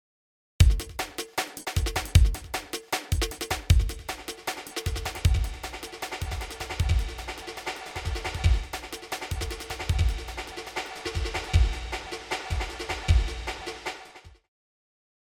リバーブリバーブ
残響音を擬似的に作り出せるエフェクト。
風呂場やトンネルの中のような音響効果が得られます。
reverb.mp3